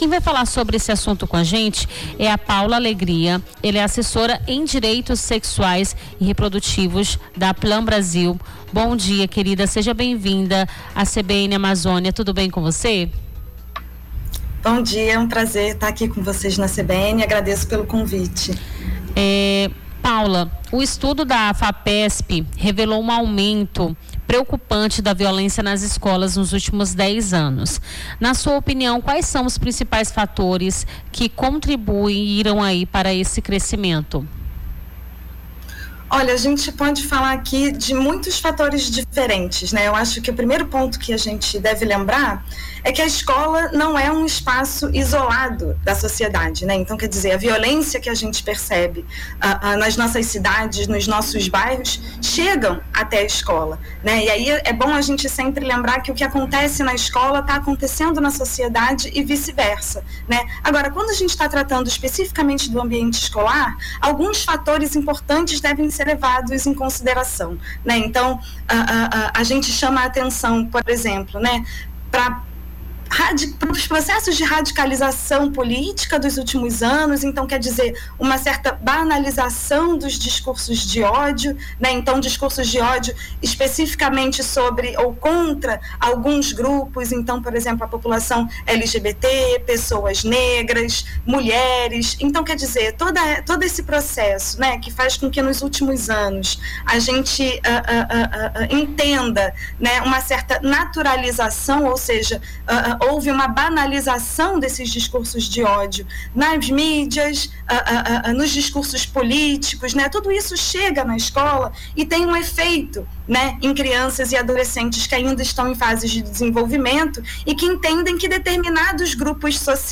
Nome do Artista - CENSURA - ENTREVISTA (VIOLENCIA ESCOLAR) 17-06-25.mp3